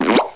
00695_Sound_pop.mp3